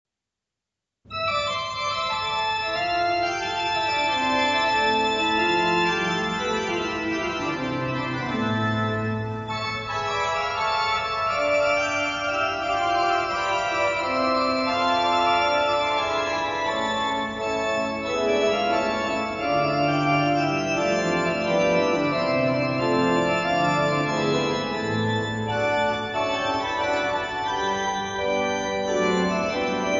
organo